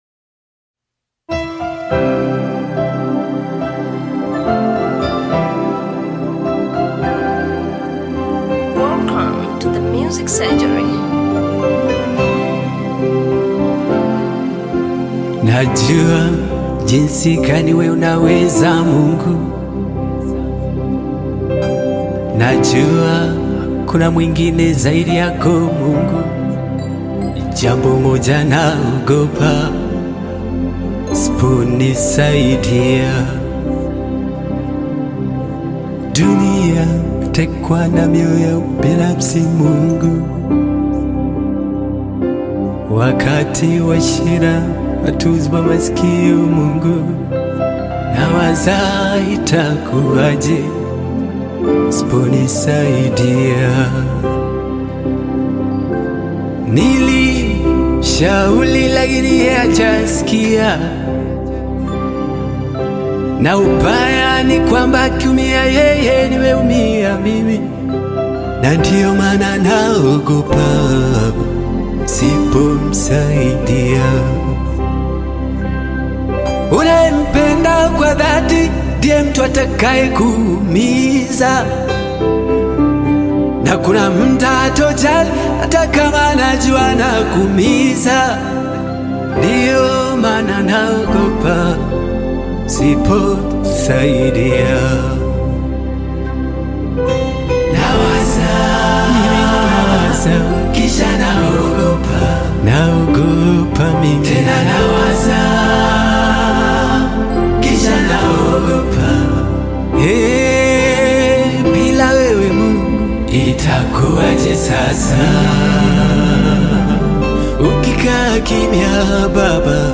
Tanzanian Gospel artist, singer, and songwriter
gospel song